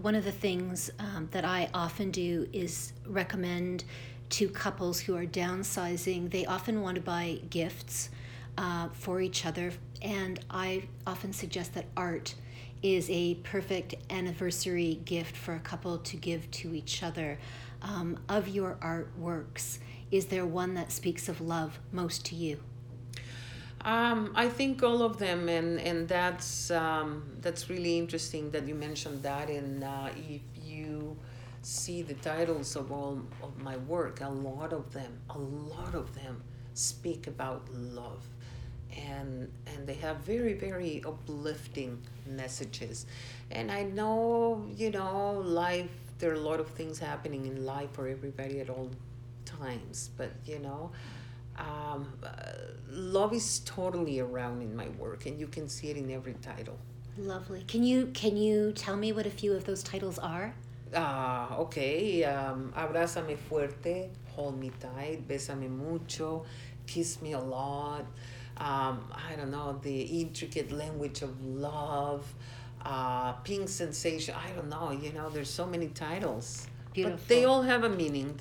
SPOTLIGHT | In Conversation